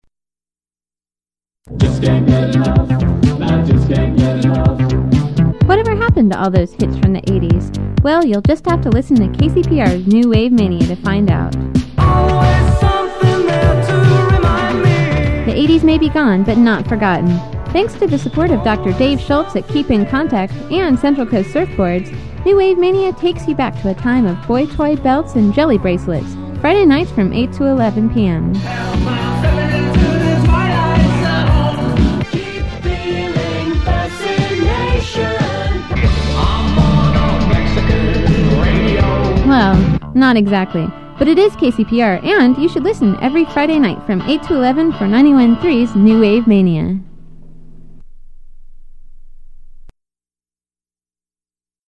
• Audiocassette